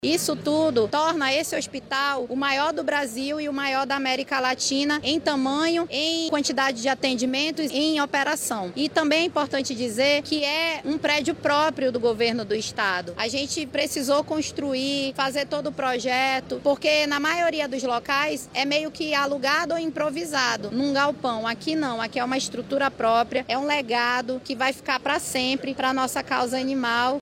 A secretária de Estado de Proteção e Bem-Estar Animal, Joana D’arc, disse que a estrutura é maior do Brasil e da América Latina em tamanho, capacidade de atendimentos e operação.